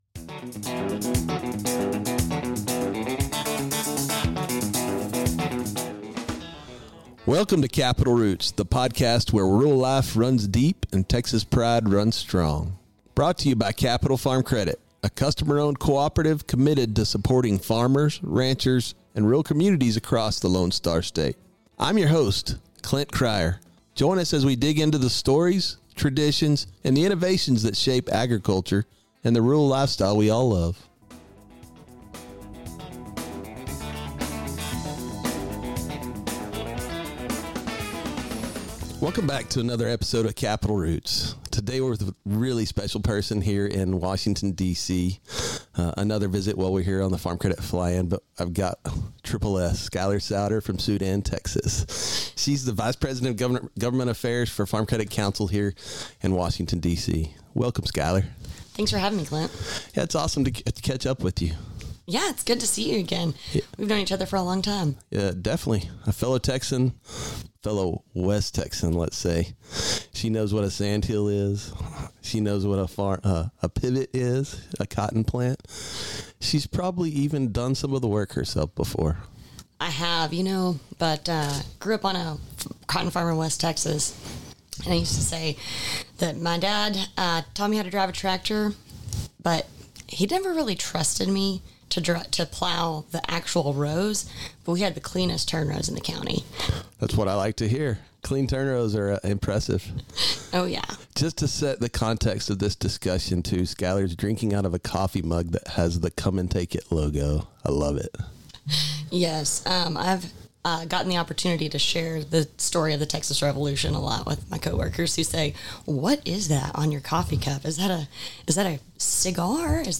Recorded in Washington, D.C. during the 2025 Farm Credit Fly-In